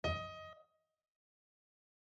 DSharp_REDiese.mp3